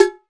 Index of /90_sSampleCDs/NorthStar - Global Instruments VOL-2/CMB_CwBell+Agogo/CMB_CwBell+Agogo